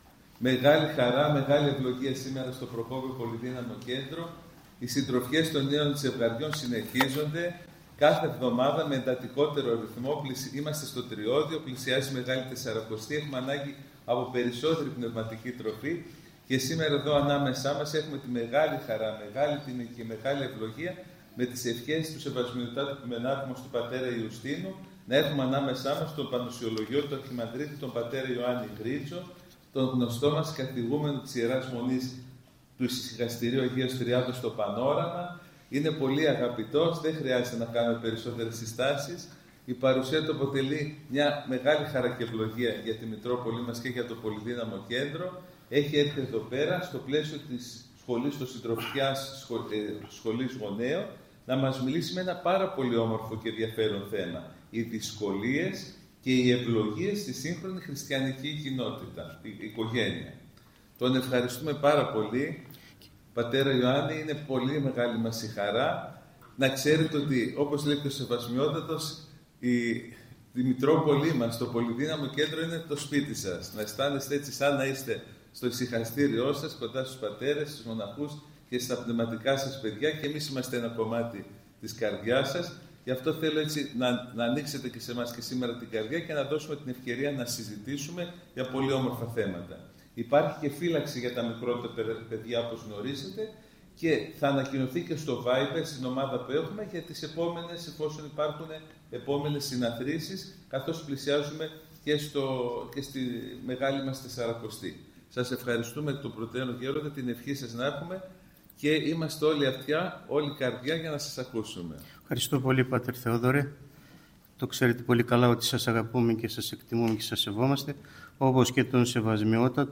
Ὁμιλία